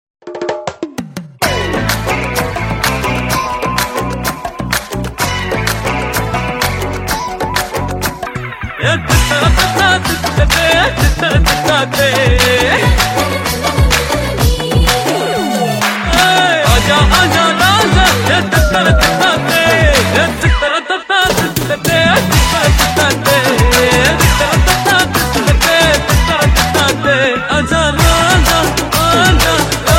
energetic and trending
with loud, clear sound